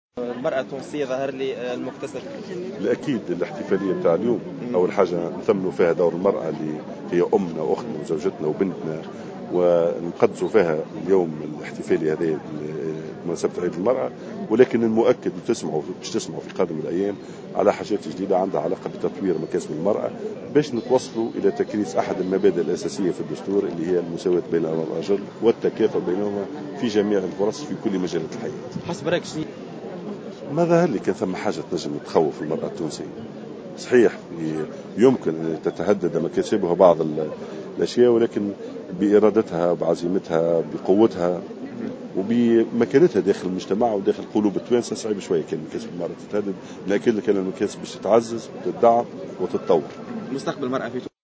أكد وزير الداخلية على هامش حضوره في تظاهرة إحتفالية "التونسية مبدعة" نظمتها وزارة المرأة بشارع الحبيب بورقيبة عشية اليوم أن هذه الإحتفالية تأتي لتثمين دور المرأة في المجتمع.